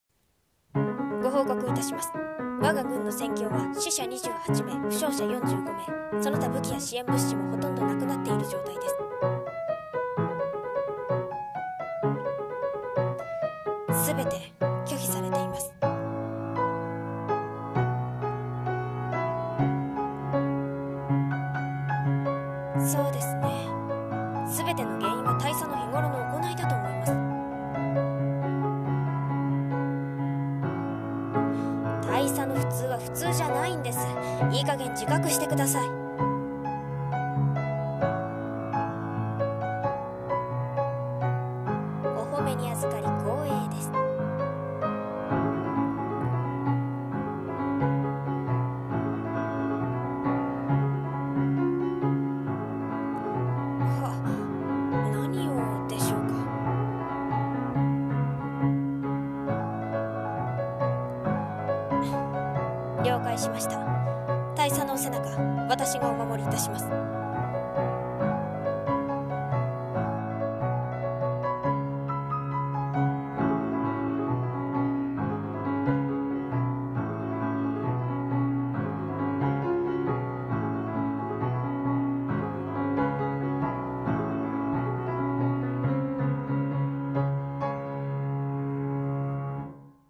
【声劇台本】